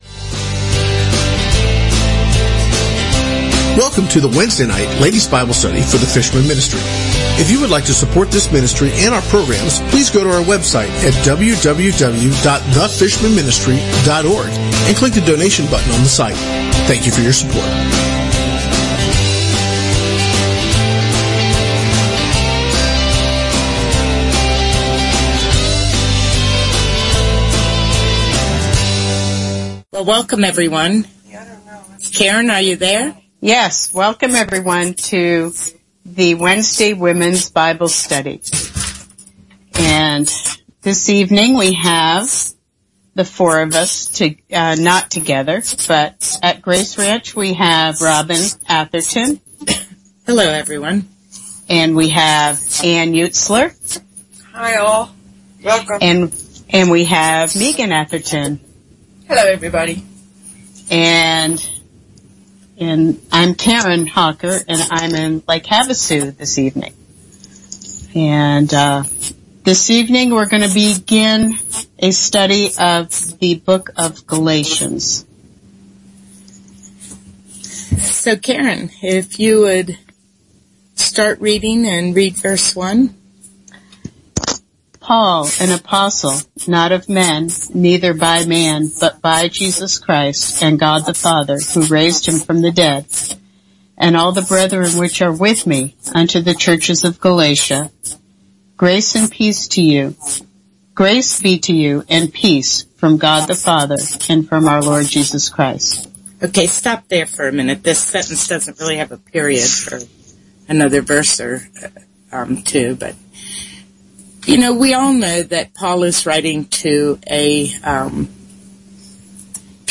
Wednesday Womens Bible Study 03/16/2016 | The Fishermen Ministry